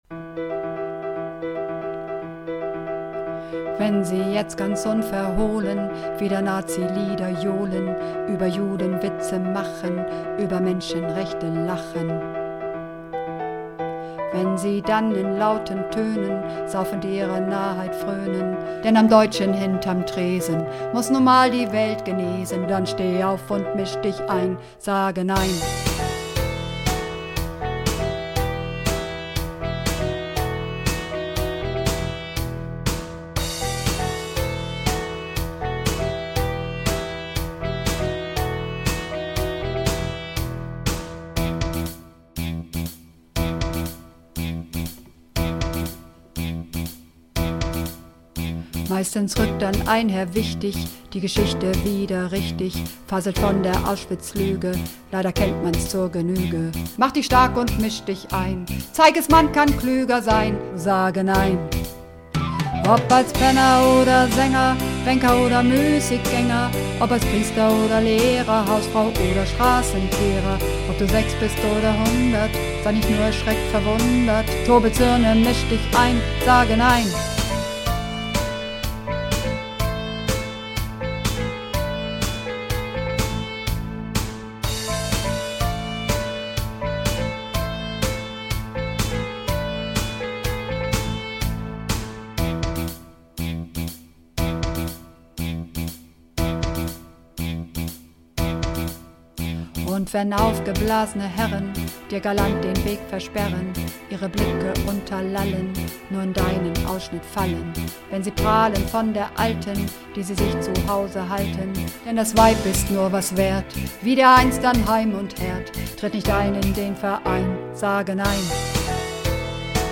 Übungsaufnahmen
Runterladen (Mit rechter Maustaste anklicken, Menübefehl auswählen)   Sage Nein (Bass)
Sage_Nein__2_Bass.mp3